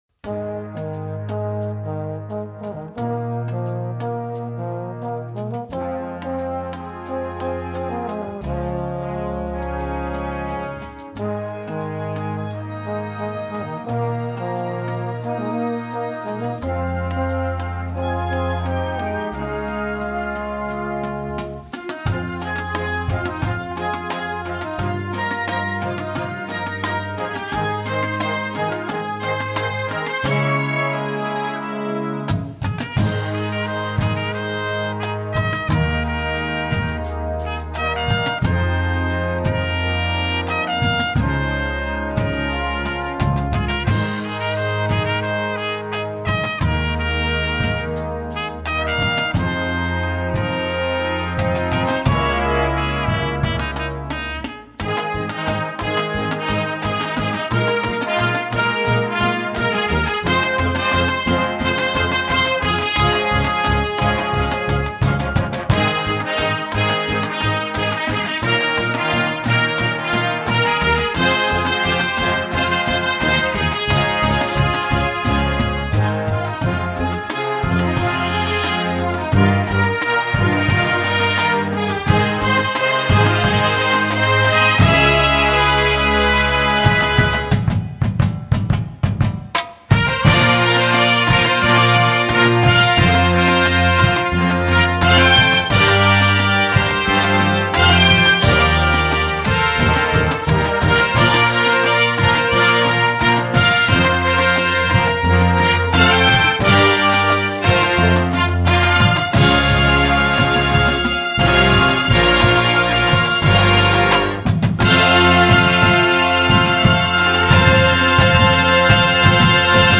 Marching-Band
Besetzung: Blasorchester